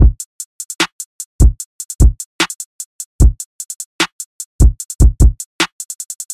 • All recreated to 99.9% accuracy from the biggest hit beats